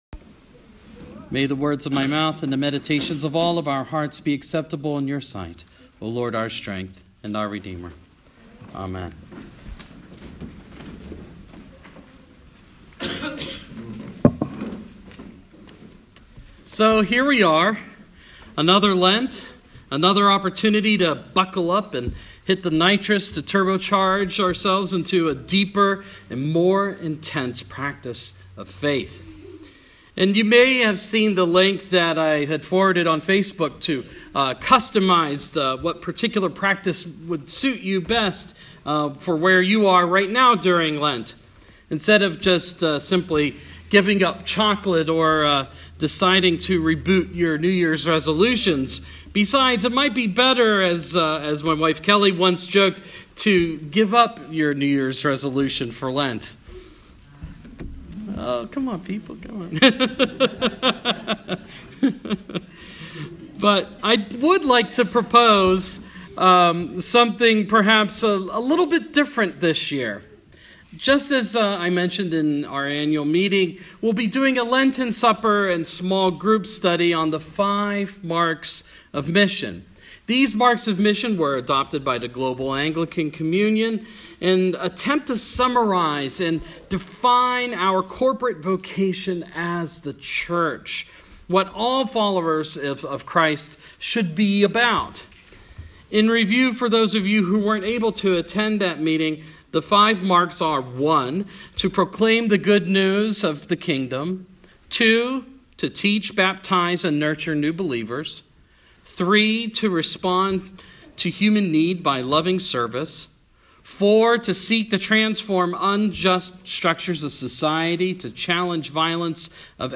Sermon – Ash Wednesday 2016